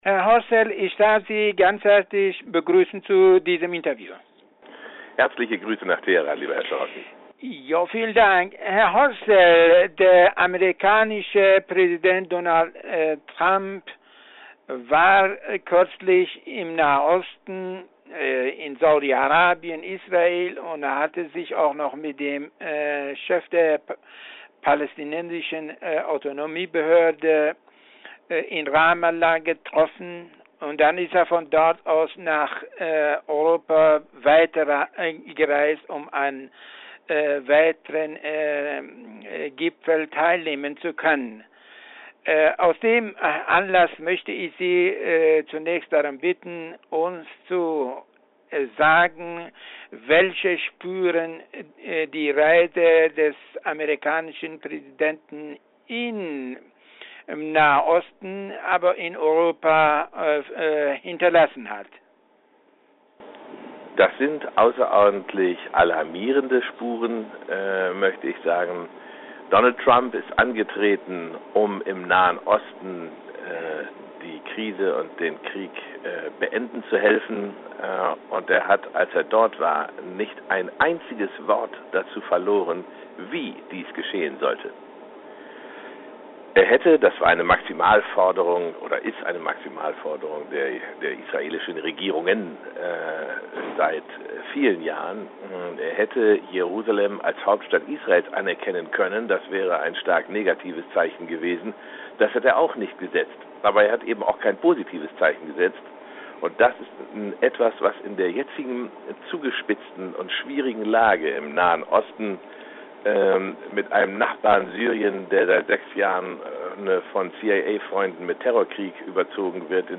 Anmerkung: Interview in voller Länge im Audio!